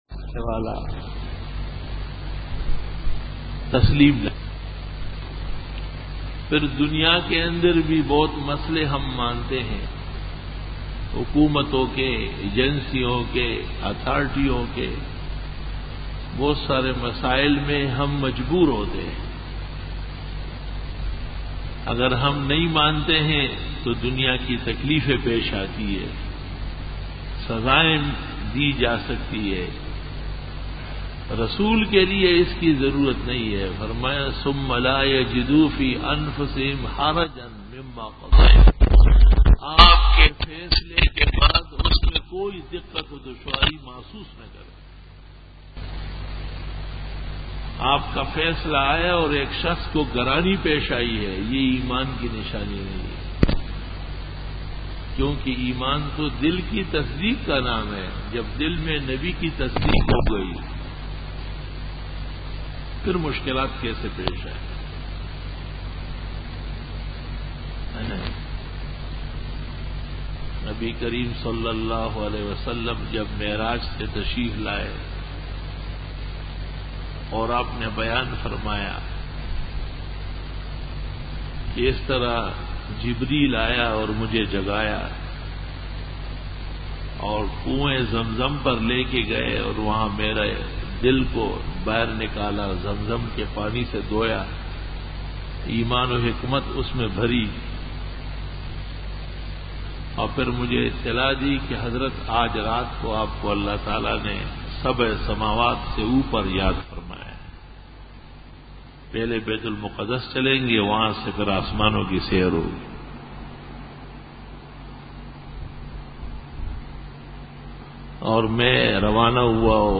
بیان جمعۃ المبارک